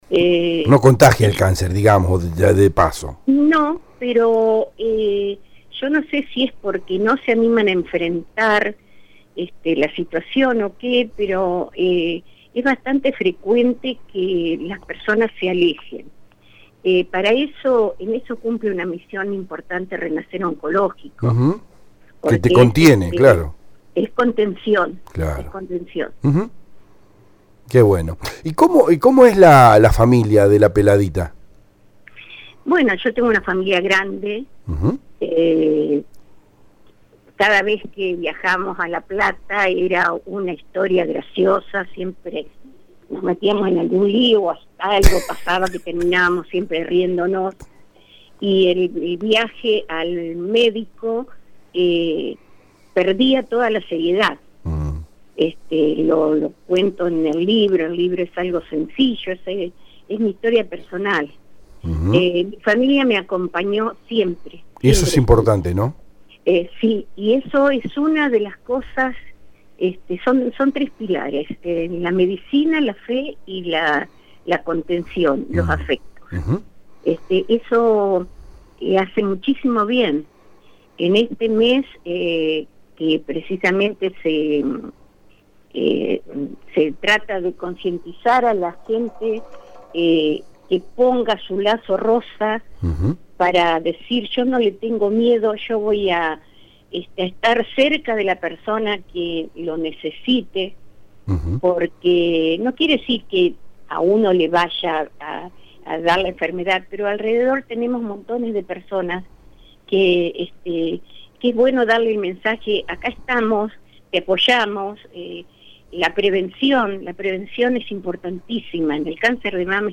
Te invitamos a escuchar también los audios que reflejan la totalidad de la charla en radio EL DEBATE.